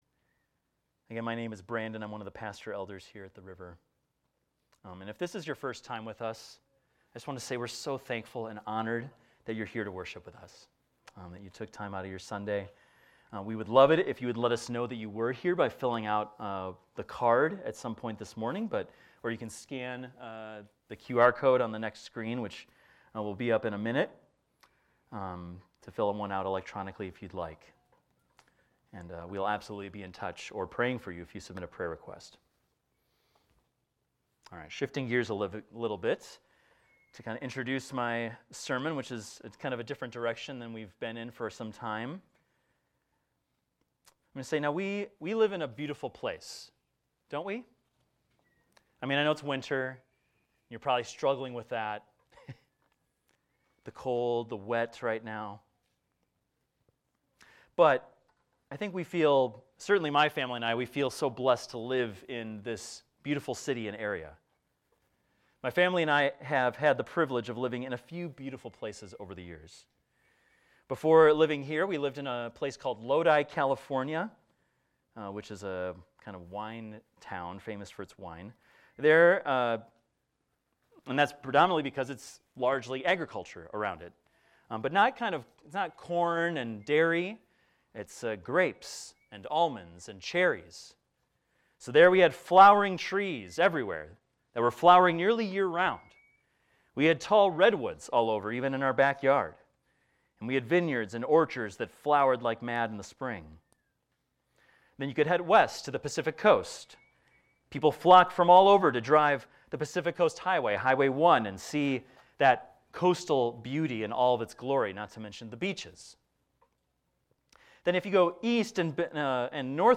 This is a recording of a sermon titled, "The Majesty of God."